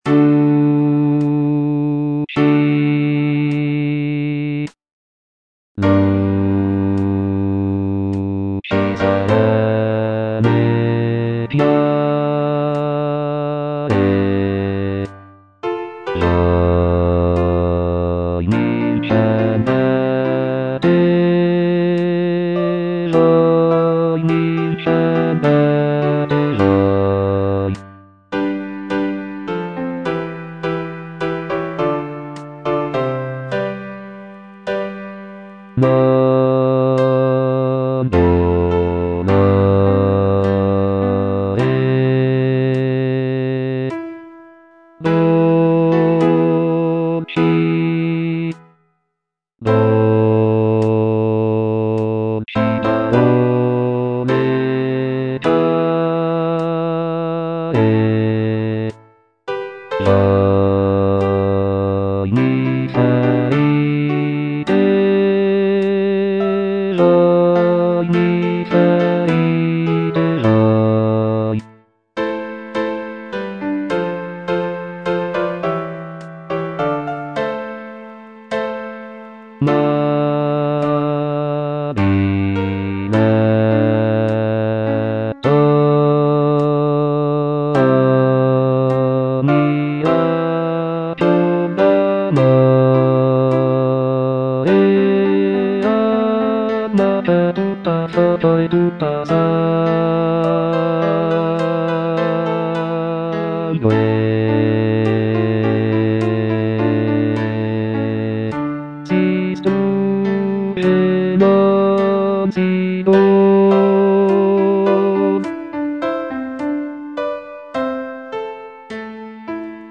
C. MONTEVERDI - LUCI SERENE E CHIARE Bass (Voice with metronome) Ads stop: auto-stop
"Luci serene e chiare" is a madrigal composed by Claudio Monteverdi, one of the most important figures in the development of Baroque music.
The madrigal is known for its intricate vocal lines and rich textures, creating a sense of serenity and clarity in the music.